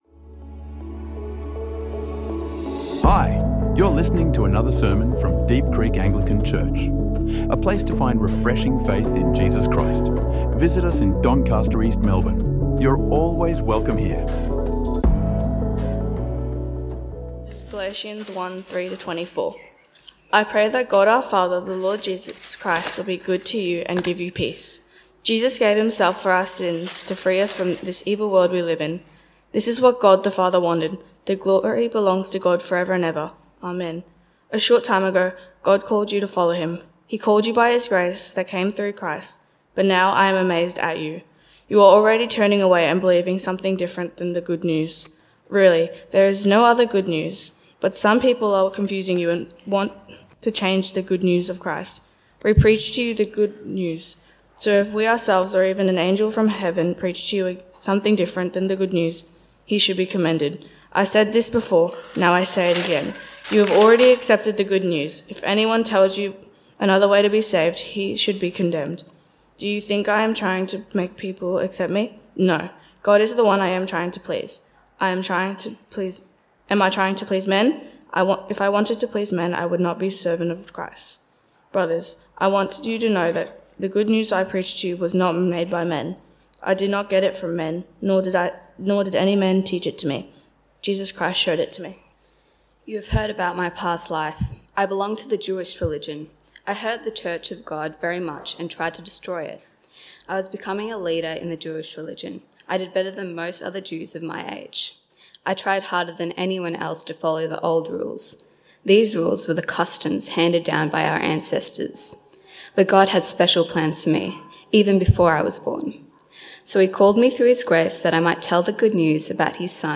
Freedom in the Gospel | Sermons | Deep Creek Anglican Church